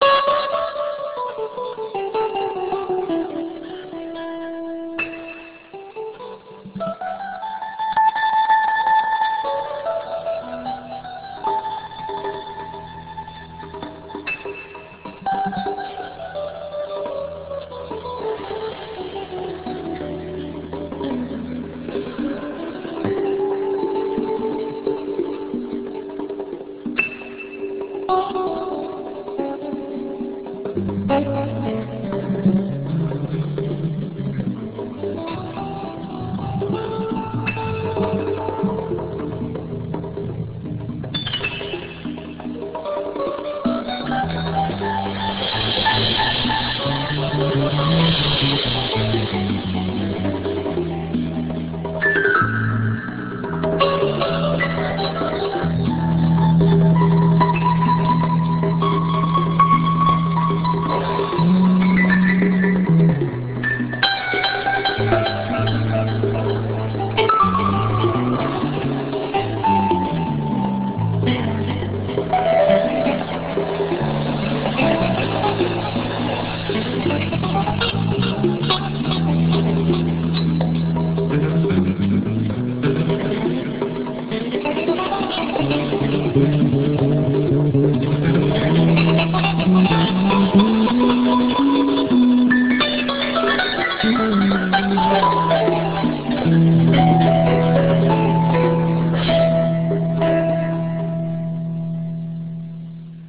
guitar
bass
piano
conga
drums